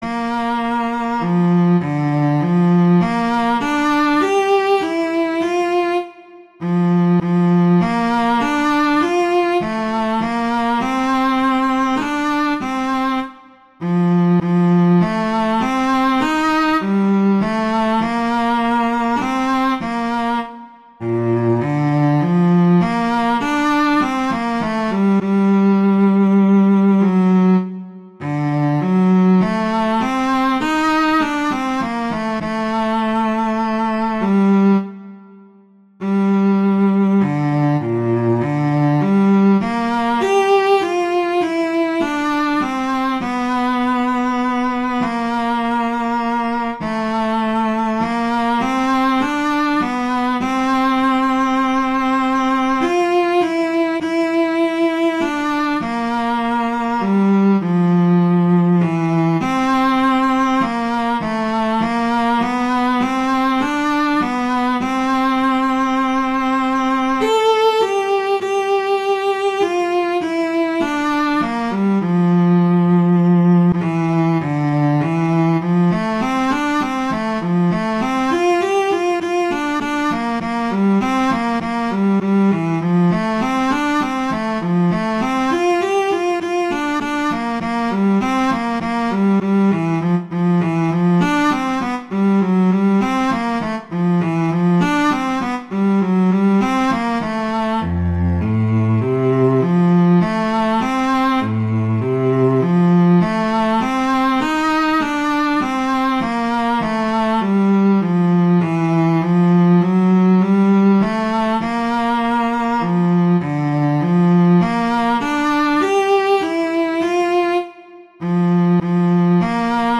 classical, instructional
Bb major
♩=100 BPM